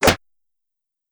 mixkit-quick-ninja-strike-2146.wav